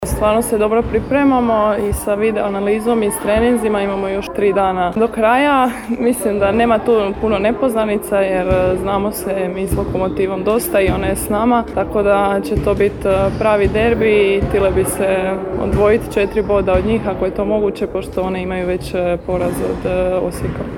Održana konferencija za medije uoči nedjeljnog derbija u kojem će snage odmjeriti Podravka Vegeta i Lokomotiva Zagreb